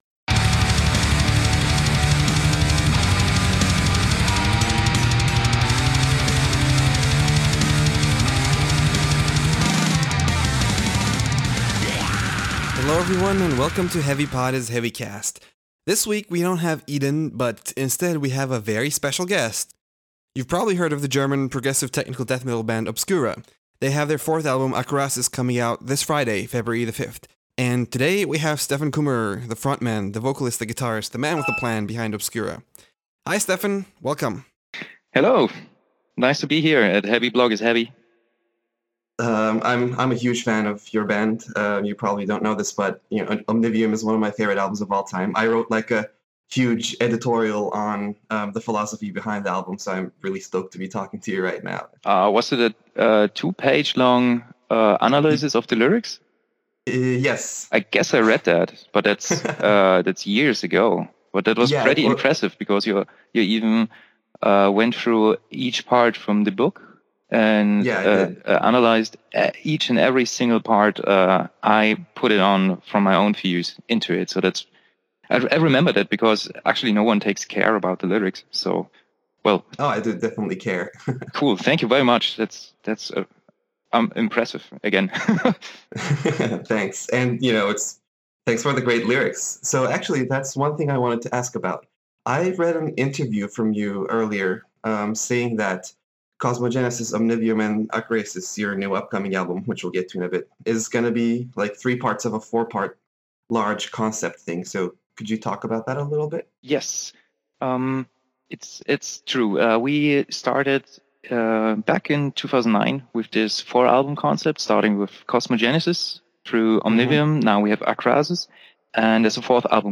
We have an in depth conversation about a lot of topics, including their upcoming album Akroasis (read my review), its concept, the themes of the lyrics, the imagery surrounding it and more!